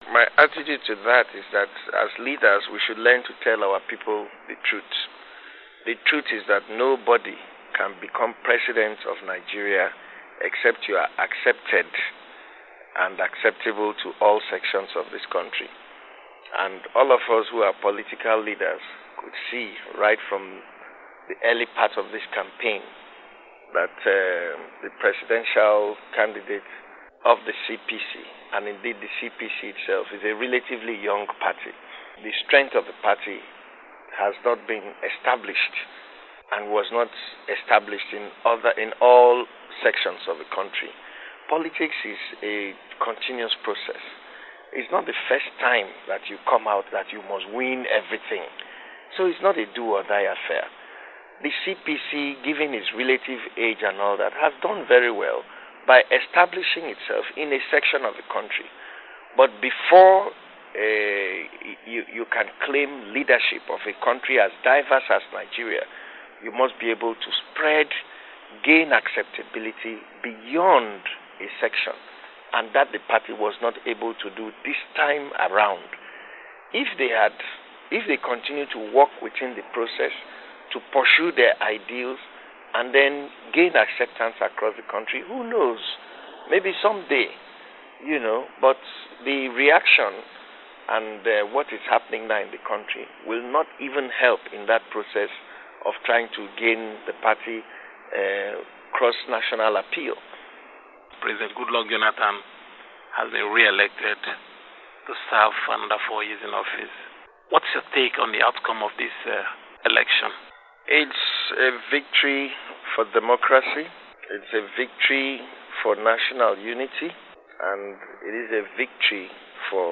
interview with Senator-elect Magnus Ngei Abe of Rivers State